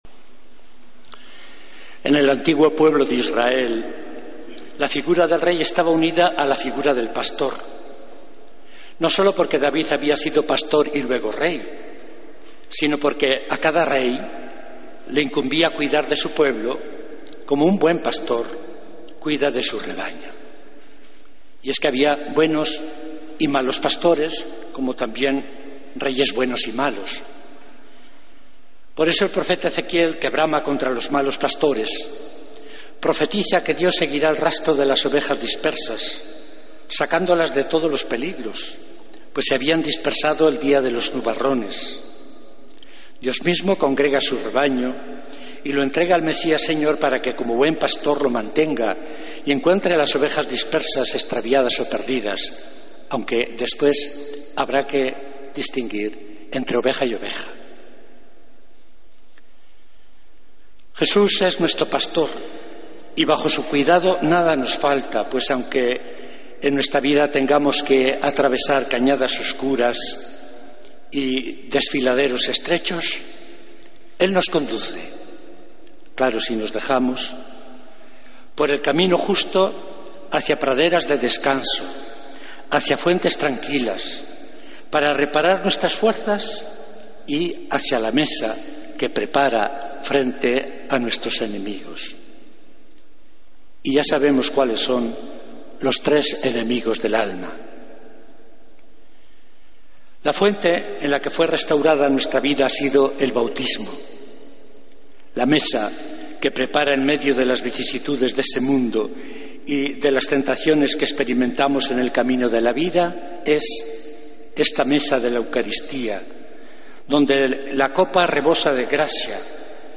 AUDIO: Homilía del Domingo 23 de Noviembre de 2014